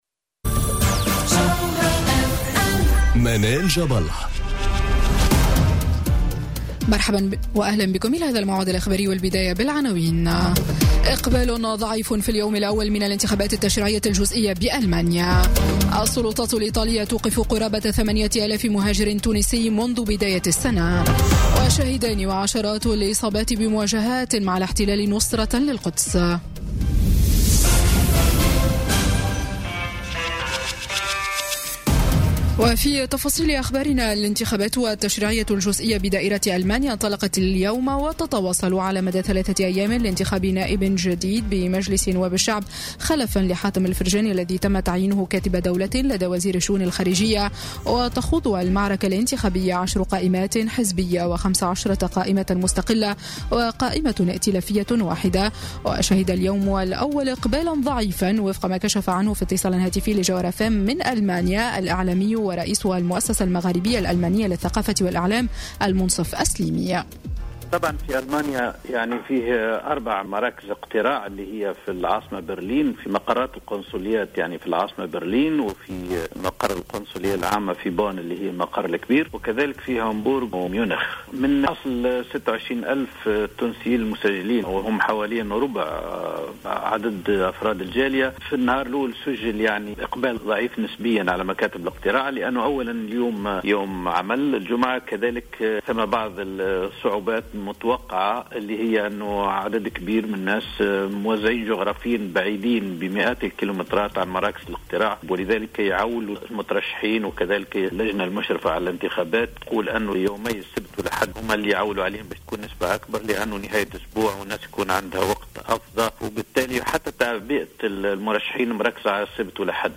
نشرة أخبار السابعة مساءً ليوم الجمعة 15 ديسمبر 2017